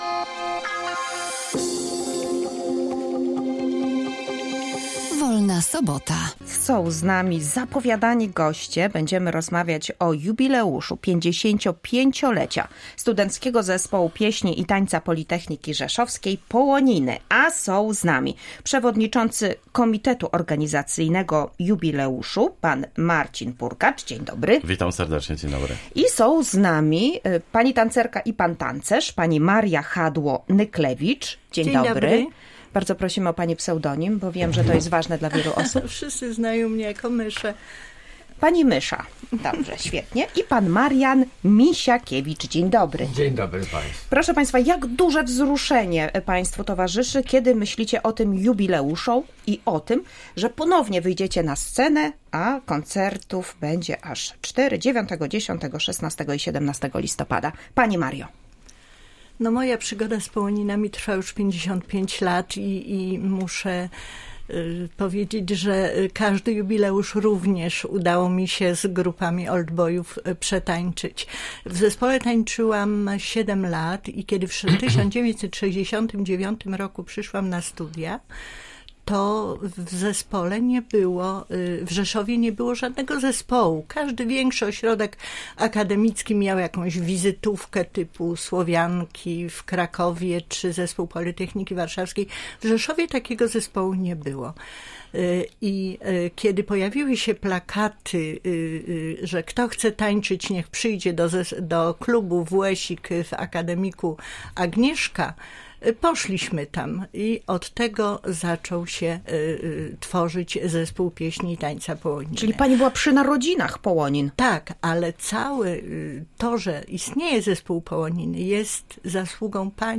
W audycji „Wolna Sobota” byli oraz obecni tancerze wspominali swój czas w Połoninach.